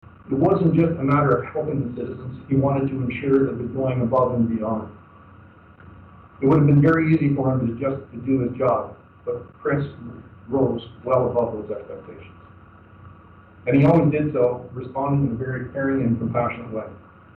Deputy Chief Barry was sworn in by Mr. Justice Stephen Hunter at the new Belleville Police Service headquarters.
chief-callaghan.mp3